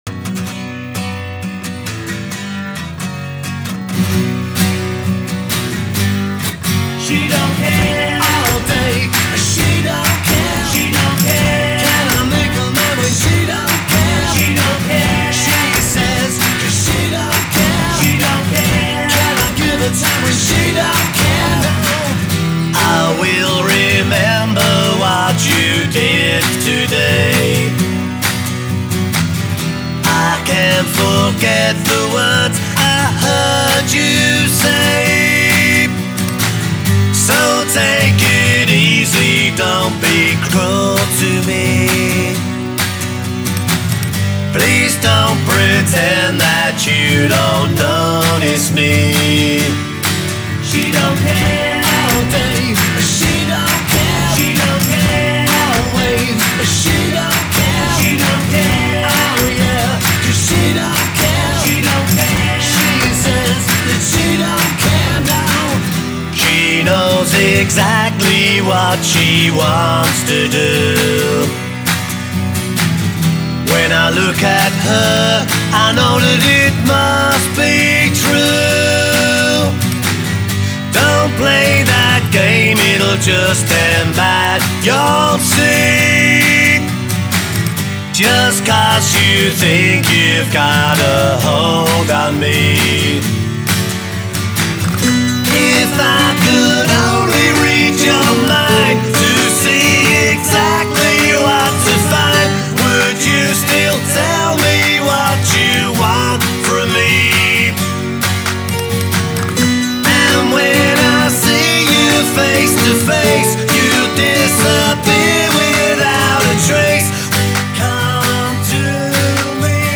the other featuring a more flamenco-style rhythm.